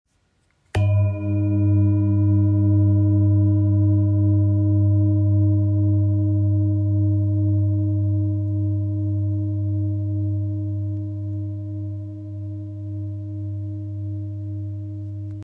Master Series Singing Bowls 30 – 33cm
33cm Mallet
Every Master Series Singing Bowl is made to deliver a powerful, harmonious sound.
With clarity, warmth, and a long-sustaining tone, these bowls create an enveloping resonance that enhances meditation, sound baths, and therapeutic sessions.
A-33cm-mallet.m4a